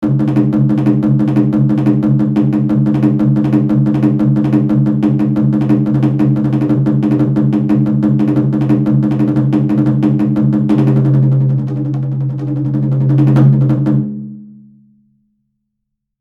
Japanese drum performance #4 (sound effects)
Musical instrument
This is the sound effect of the Japanese drum performance....